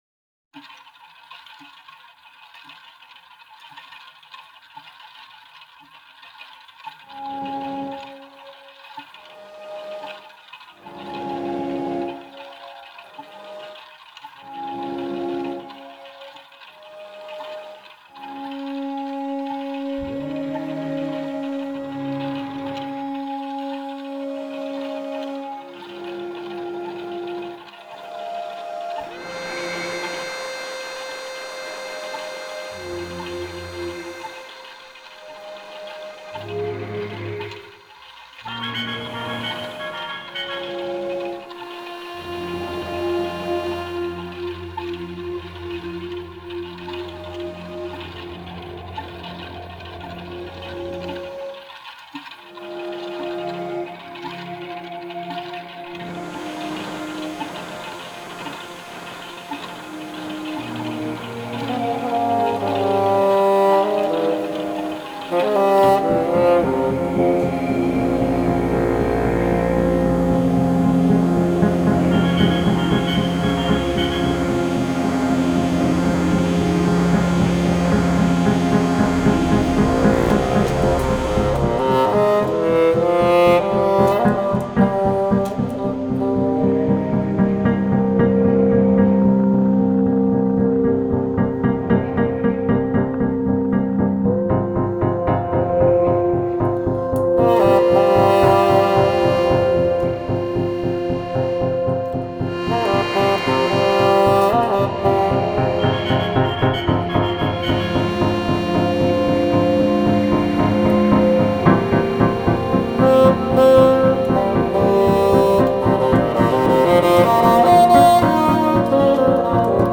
all acoustic and electronic instrument performances